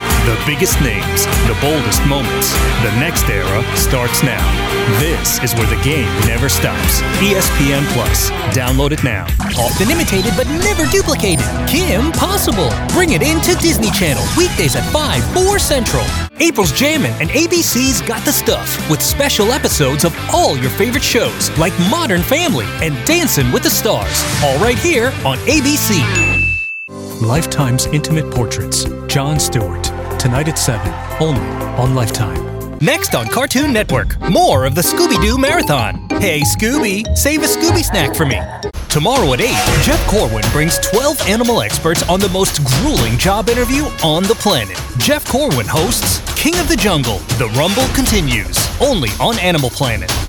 Male
Promo/Imaging Demo - Tv Radio
Words that describe my voice are conversational, relatable, genuine.